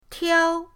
tiao1.mp3